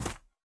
WAV · 21 KB · 單聲道 (1ch)